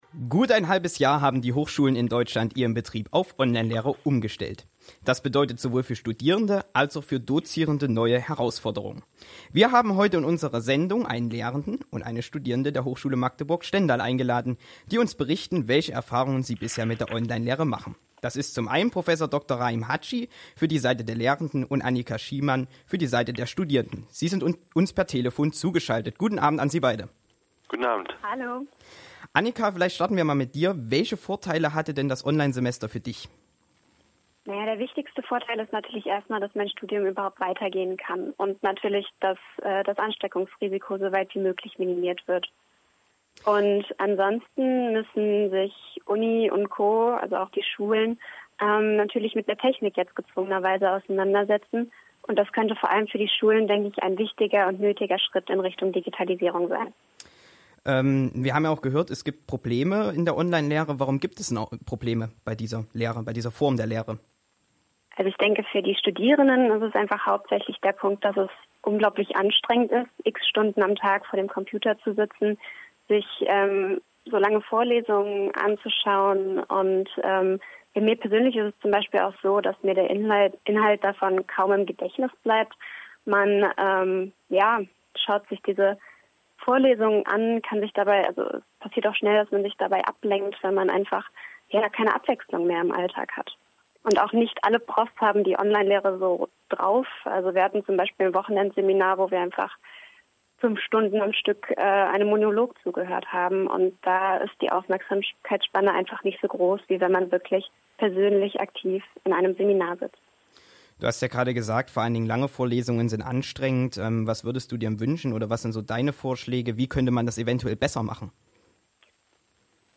(Live-Interview aus omnifrequent vom 2.12.2020)